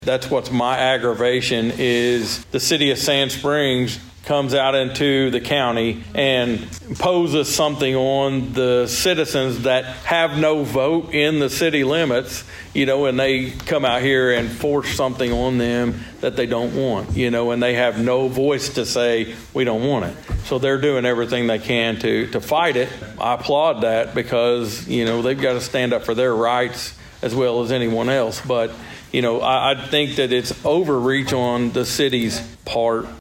As discussed during Monday's Osage County Commissioners meeting though, there has been some pushback to the development of the data center.